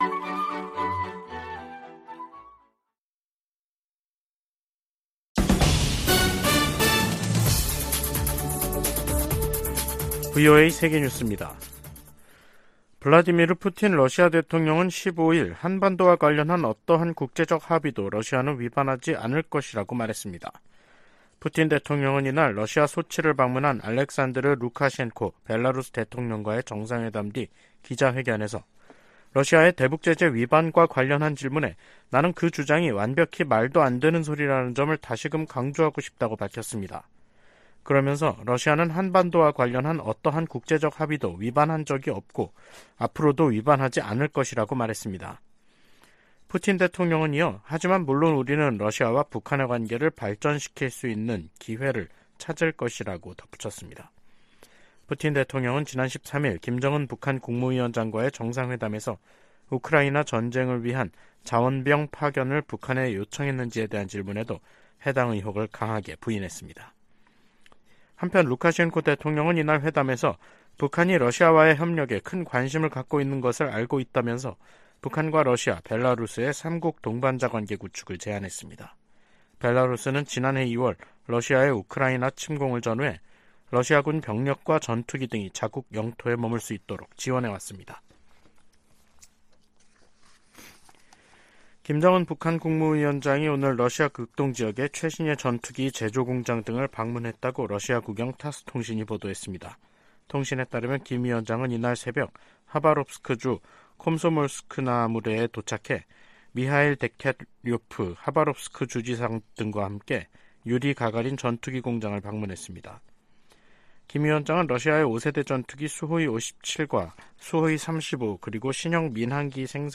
세계 뉴스와 함께 미국의 모든 것을 소개하는 '생방송 여기는 워싱턴입니다', 2023년 9월 15일 저녁 방송입니다. '지구촌 오늘'에서는 중국이 타이완에 대한 미국의 무기 판매에 대응해 미국 군수기업 록히드마틴과 노스롭그루먼에 제재를 단행한 소식 전해드리고, '아메리카 나우'에서는 전미자동차노조(UAW)가 15일 제너럴모터스(GM), 포드, 스텔란티스 공장에서 파업에 돌입한 이야기 살펴보겠습니다.